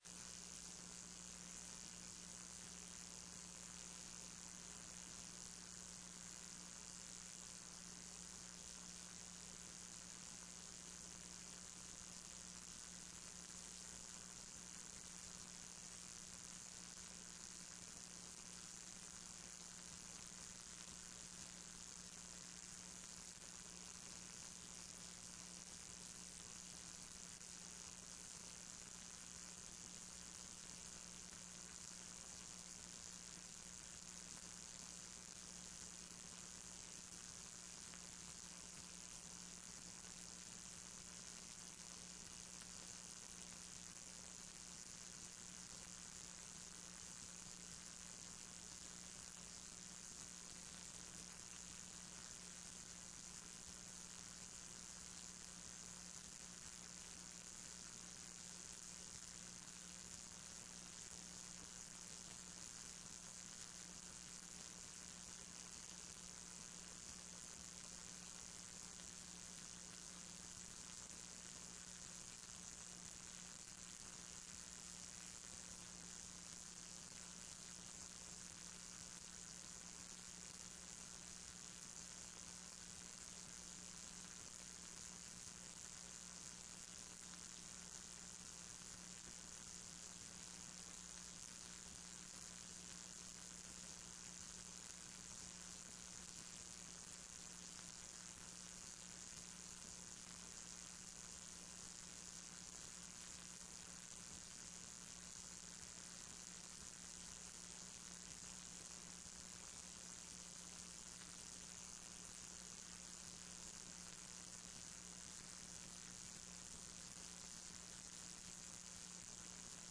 TRE-ES sessão do dia 24 09 14